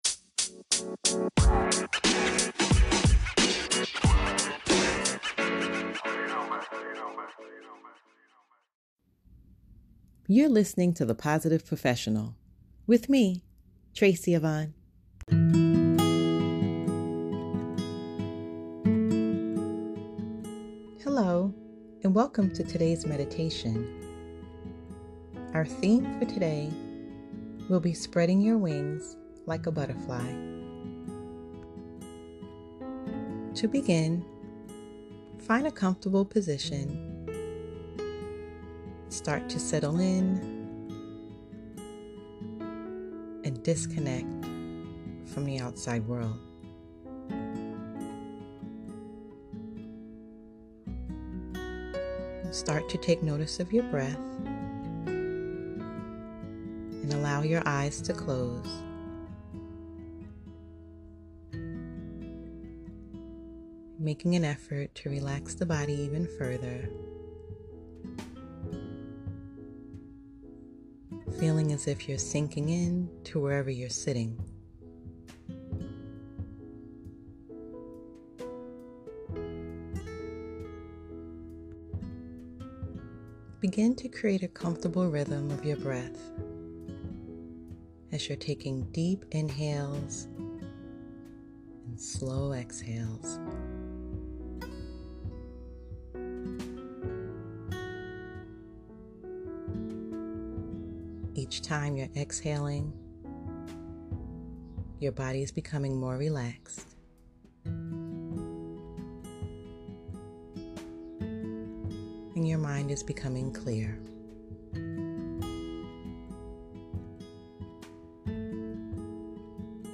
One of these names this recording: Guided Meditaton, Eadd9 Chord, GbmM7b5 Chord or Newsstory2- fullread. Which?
Guided Meditaton